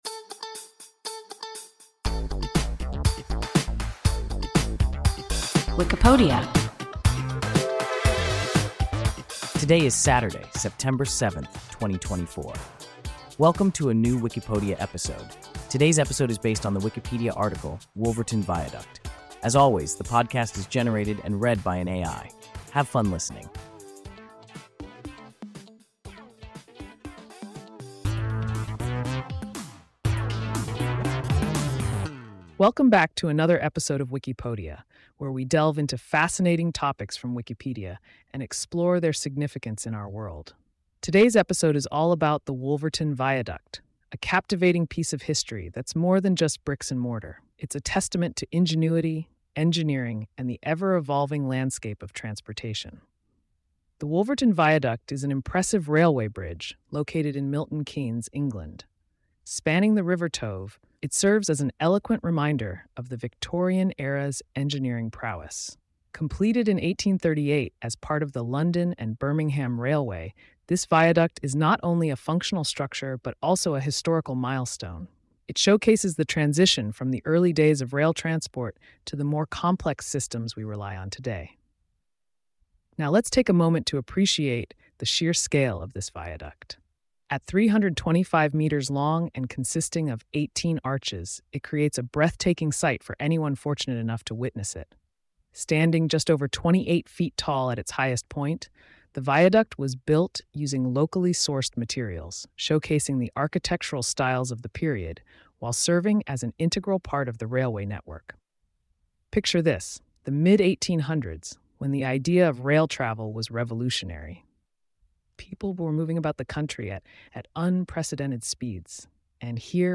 Wolverton Viaduct – WIKIPODIA – ein KI Podcast